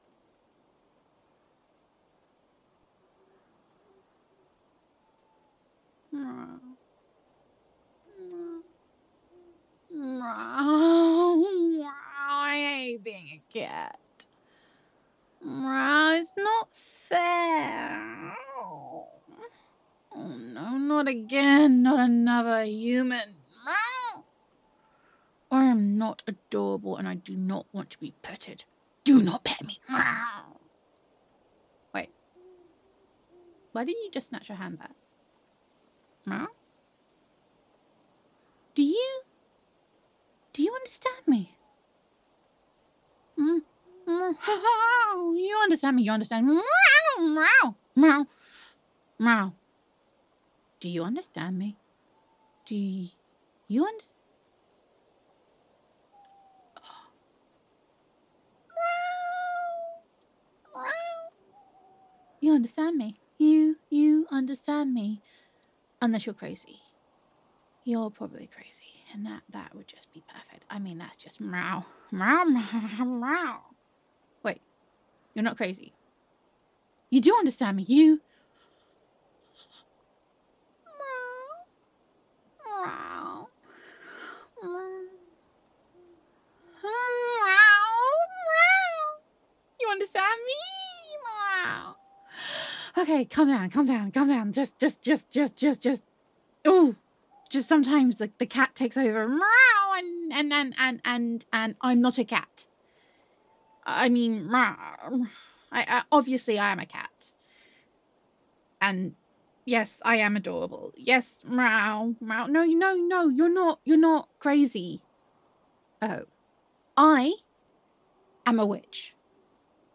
[F4A] Listen, I'm Fur Real! [Cattitude][Transformation][Supernatural][You Can Understand Me?][Witch Roleplay][Cat Sounds][Gender Neutral][A Clumsy Witch Has Accidentally Turned Herself a Cat and She Is Not Happy About It]